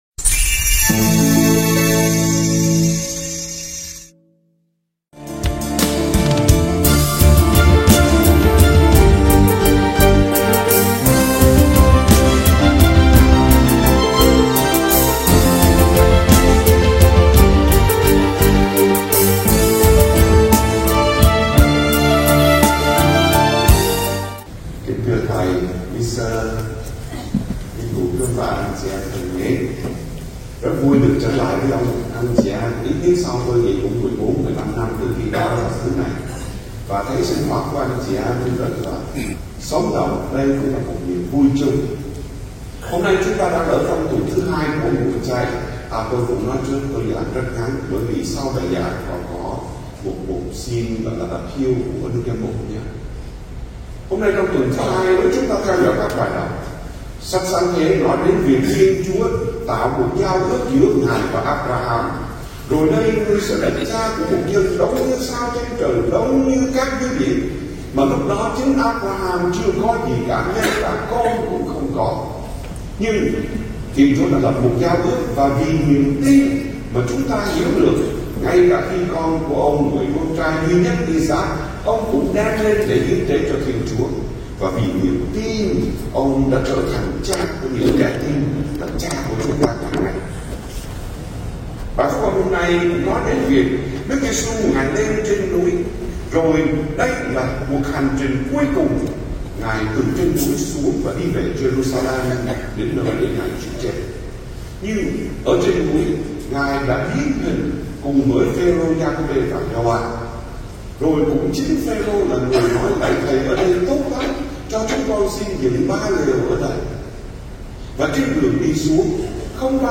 BÀI GIẢNG MÙA CHAY